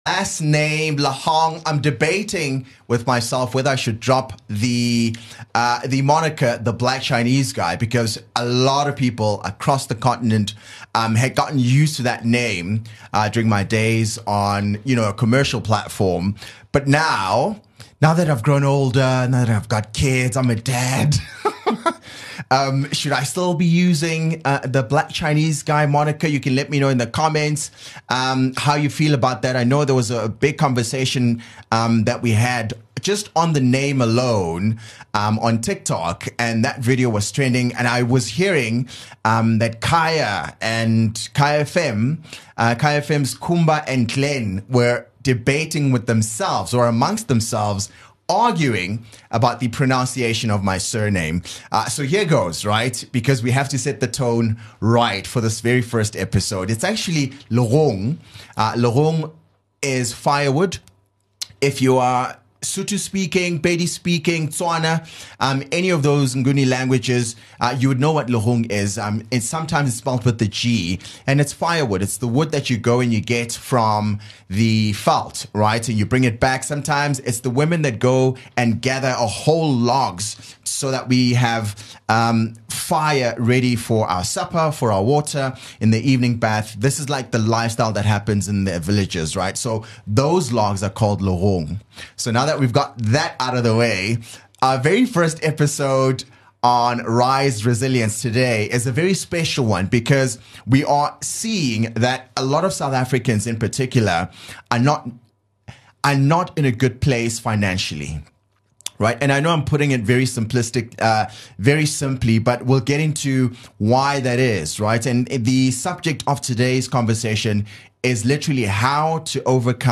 joining him in studio